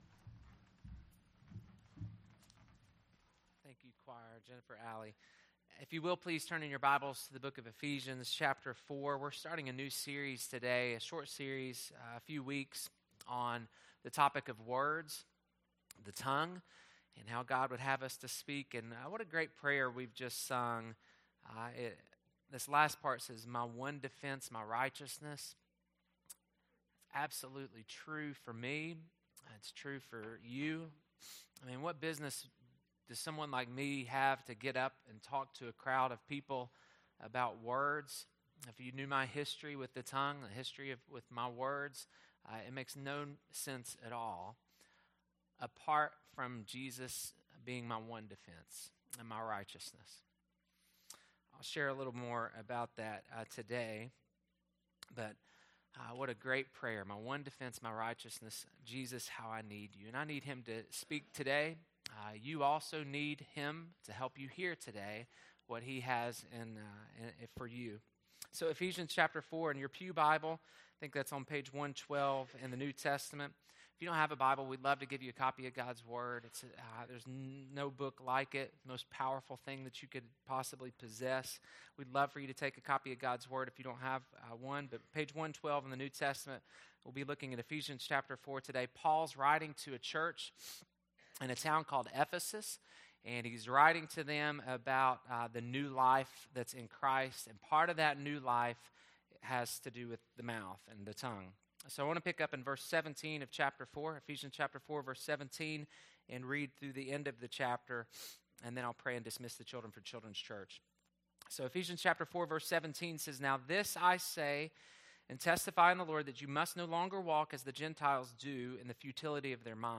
Learn a New Language This Year January 06, 2019 Listen to sermon 1.